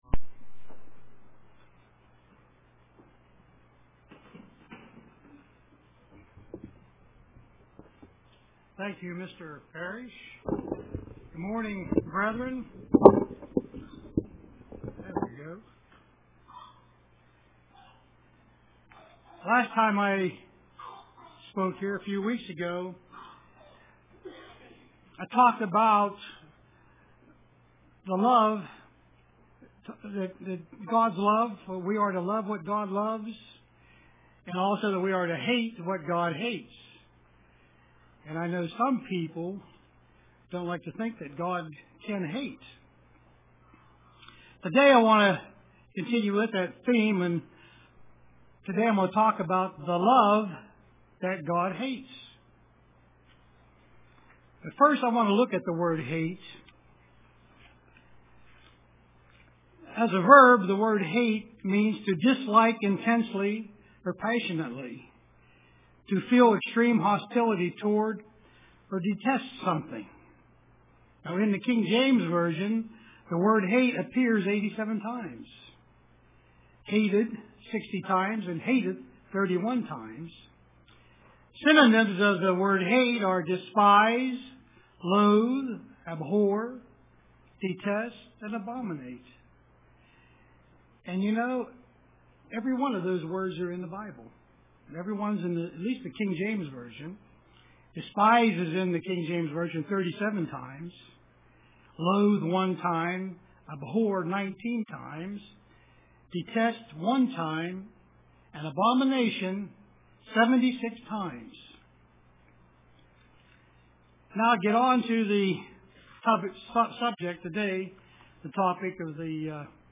Print The Love God Hates UCG Sermon